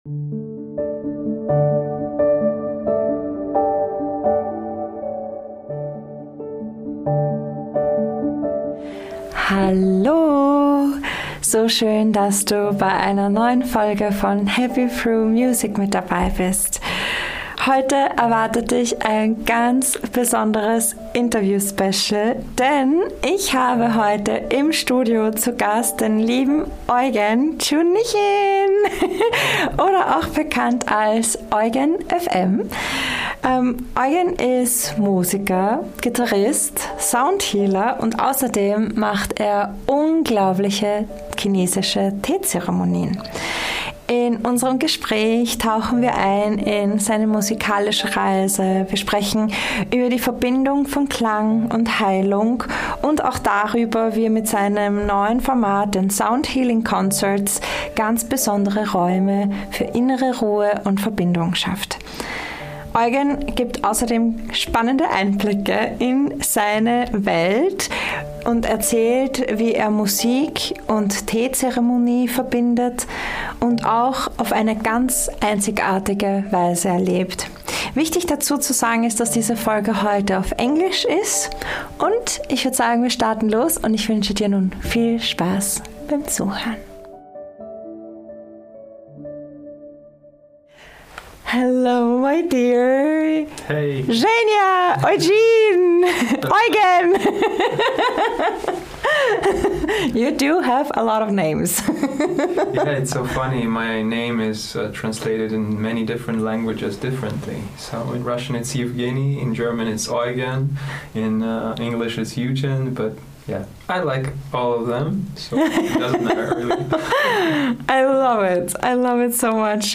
Interview Special